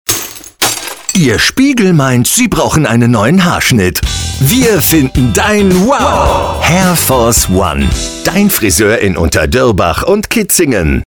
Unser Gong Werbespot